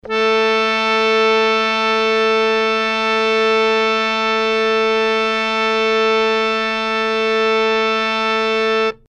interactive-fretboard / samples / harmonium / A3.mp3